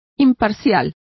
Complete with pronunciation of the translation of impartial.